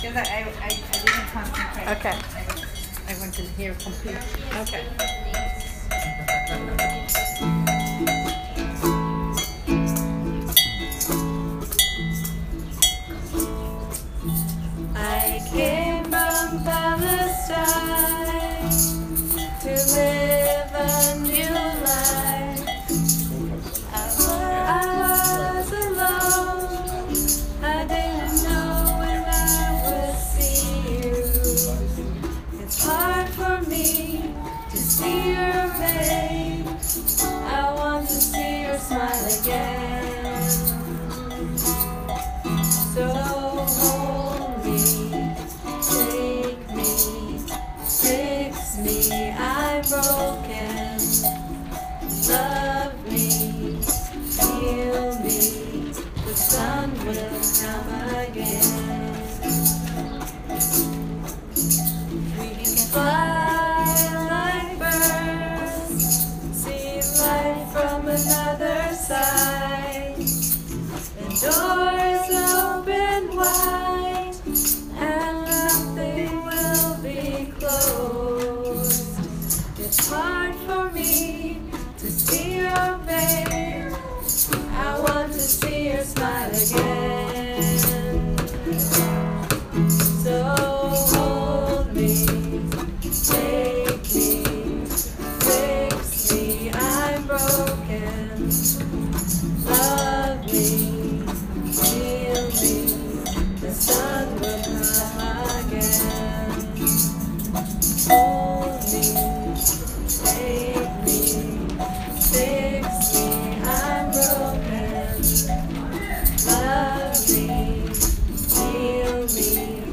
the-sun-will-come-again-runthrough.m4a